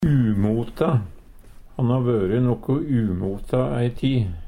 umota - Numedalsmål (en-US)